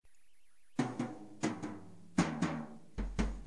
Барабаны, тарелки, палочки и вообще все, чем и на чем вы играете.